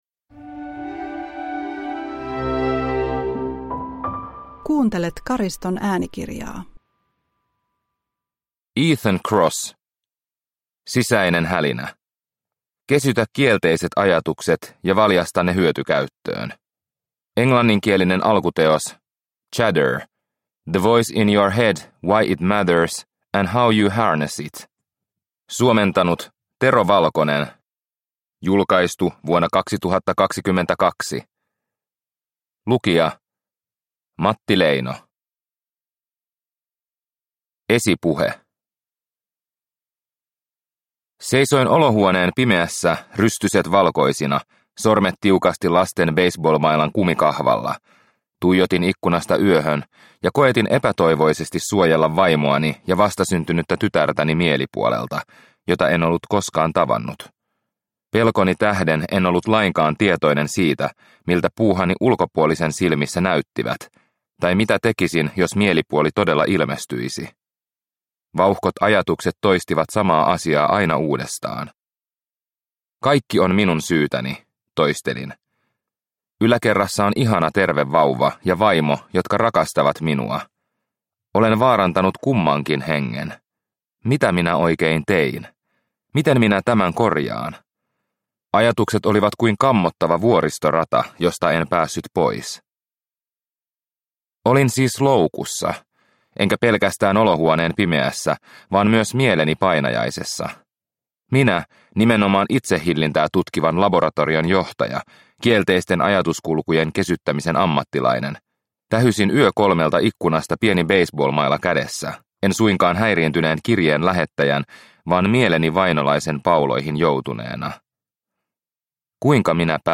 Sisäinen hälinä – Ljudbok – Laddas ner